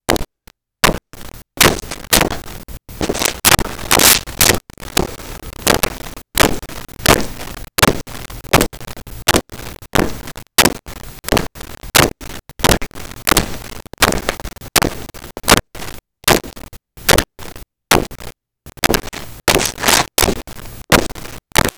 Footsteps Linoleum Slow 02
Footsteps Linoleum Slow 02.wav